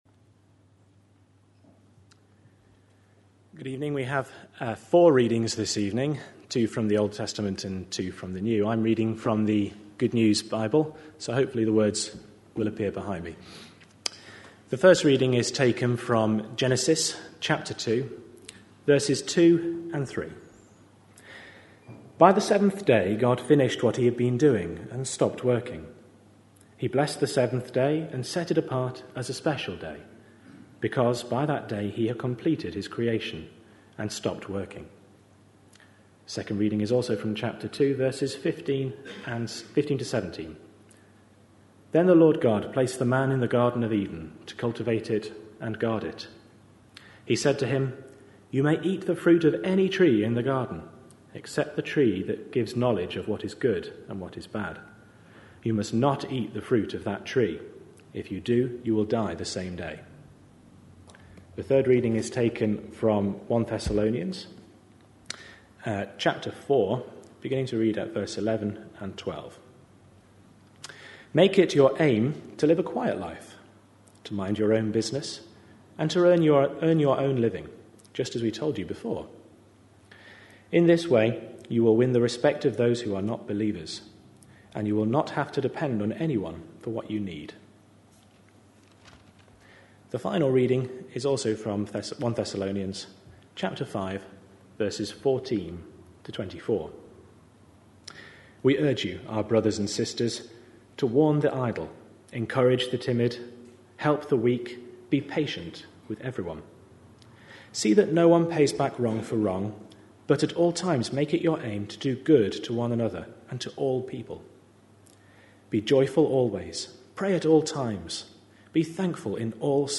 A sermon preached on 30th May, 2010, as part of our Big Issues series.